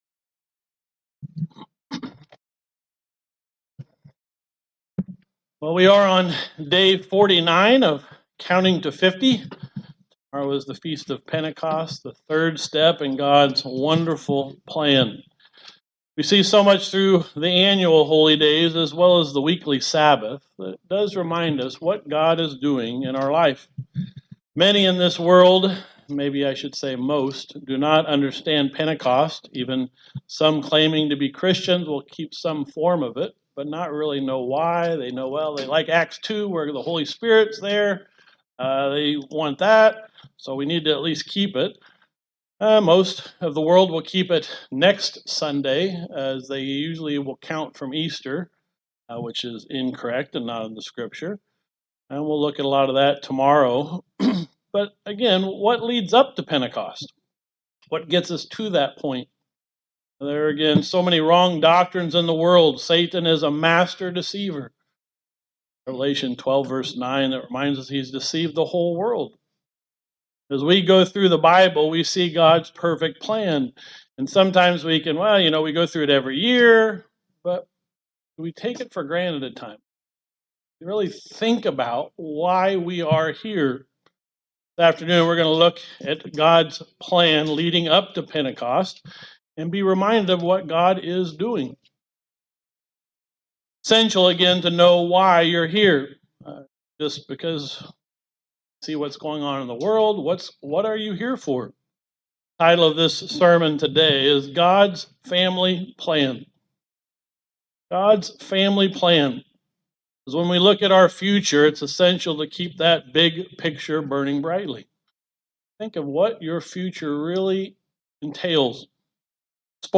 This sermon goes through God's wonderful plan as it leads up to Pentecost. A plan put together before the foundation of the world.